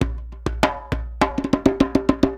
Index of /90_sSampleCDs/USB Soundscan vol.36 - Percussion Loops [AKAI] 1CD/Partition A/19-100JEMBE
100 JEMBE3.wav